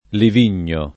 Livigno [ liv & n’n’o ]